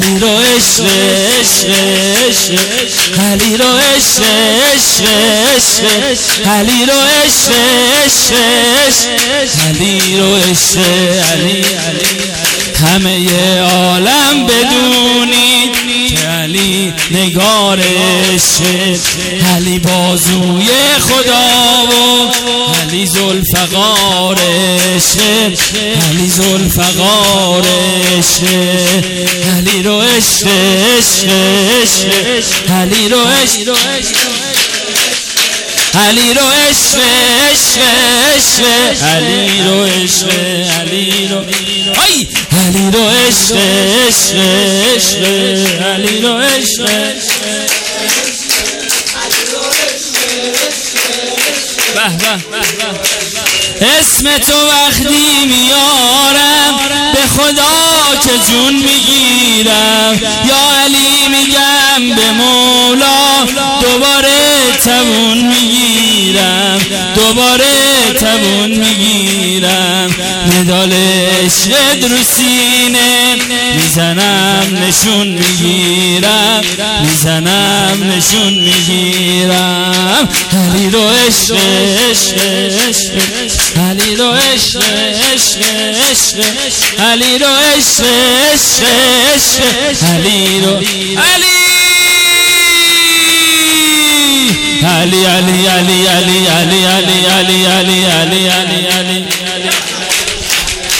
میلاد امام حسن(ع)۹۹